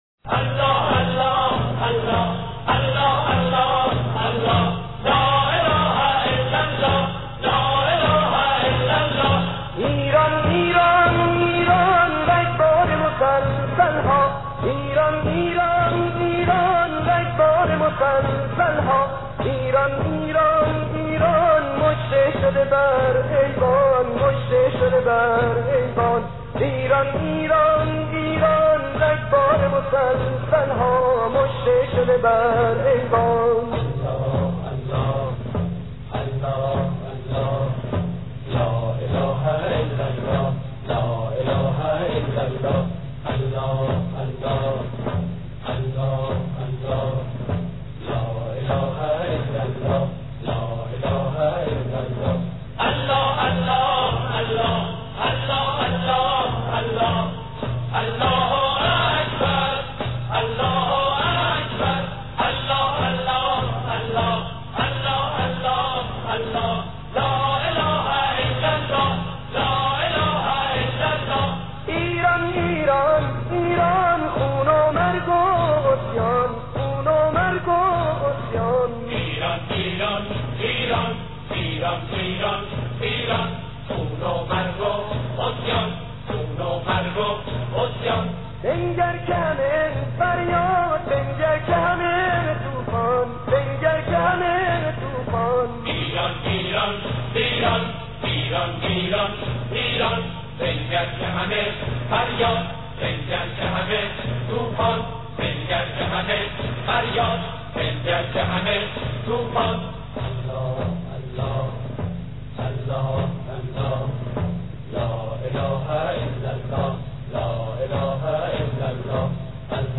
تک اهنگ ایرانی , نوستالژی